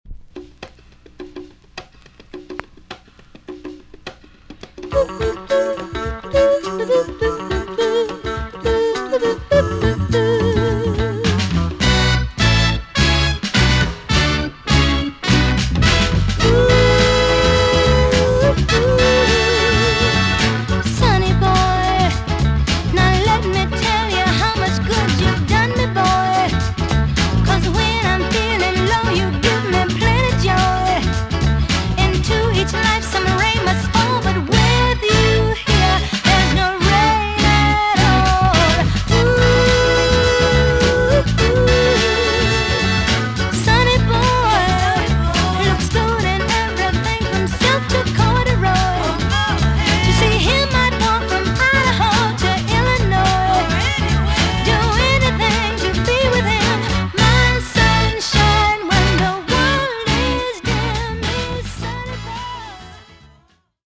EX-音はキレイです。
1968 , RARE , NICE SOUL TUNE!!